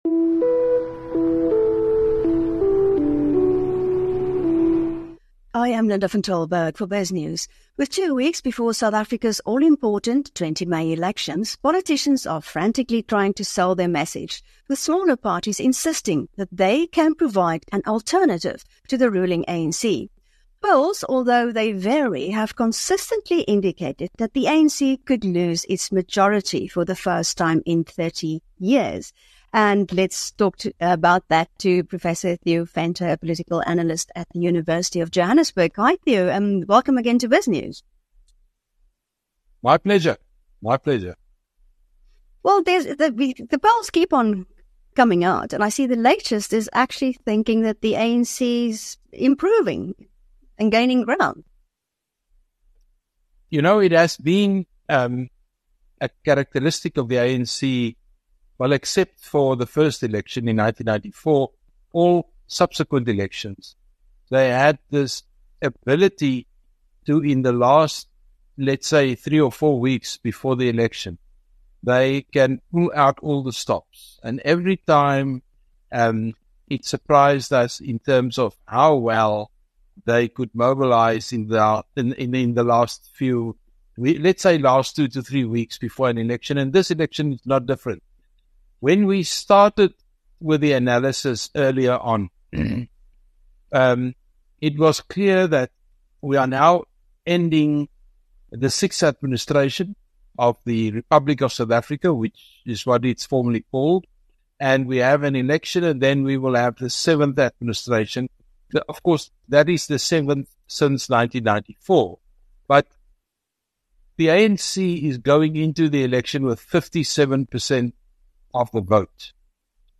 In an interview with Biznews